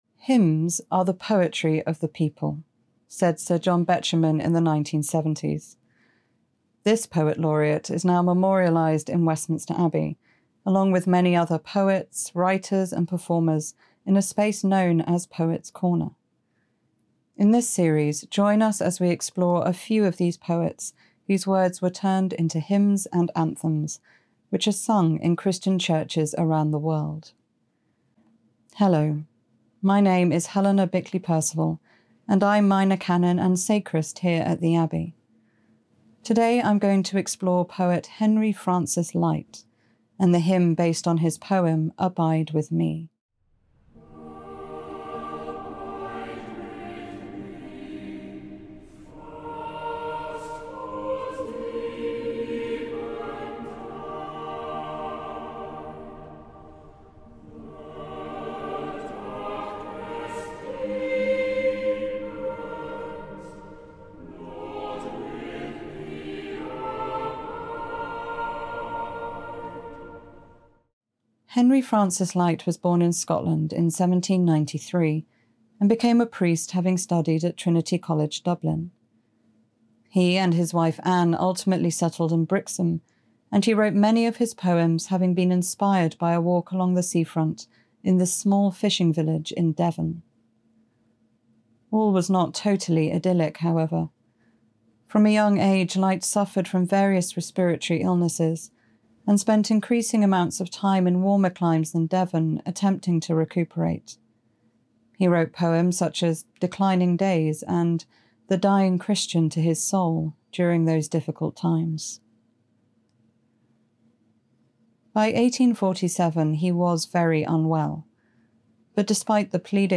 Hear staff from Westminster Abbey as they reflect on the context and the meaning of popular Christian hymns and anthems that came from poetry.